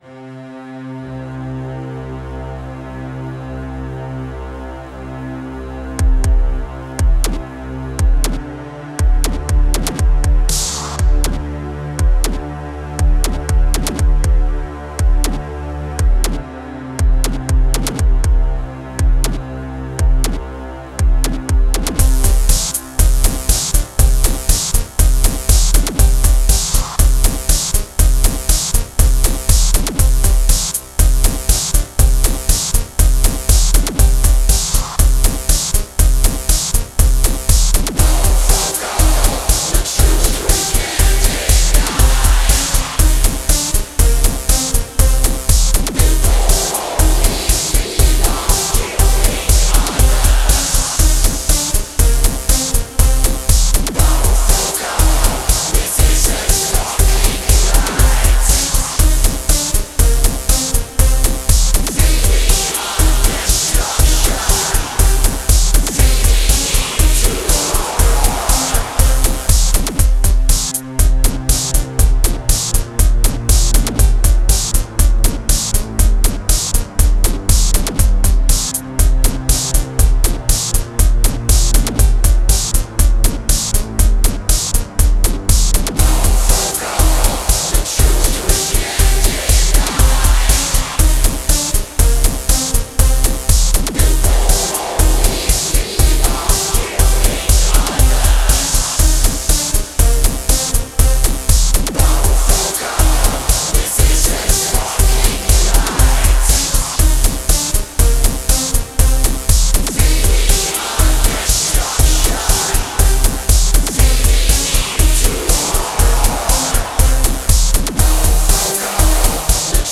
Dark Electro, Aggrotech